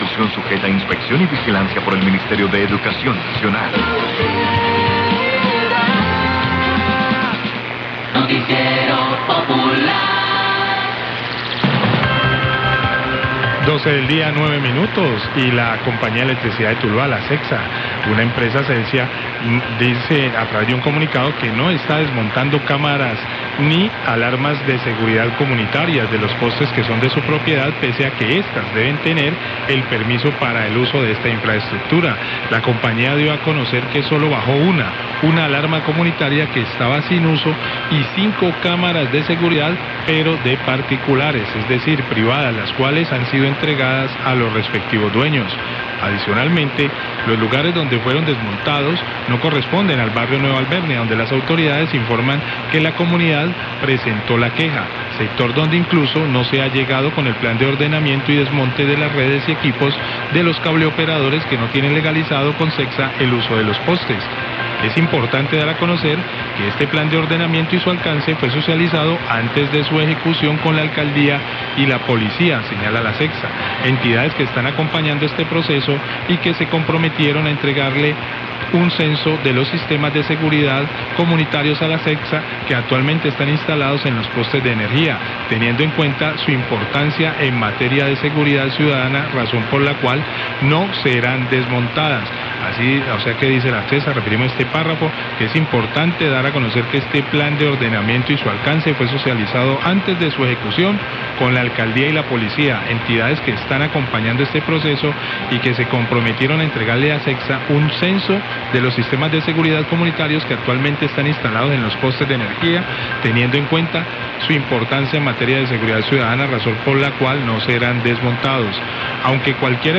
Radio
Leen al aire el comunicado enviado por CETSA donde informa que no se están desmontando cámaras ni alarmas comunitarias de los postes, pero señala los casos en los que sí se hizo desmonte de cámaras que no se encuentran en funcionamiento entre otros motivos.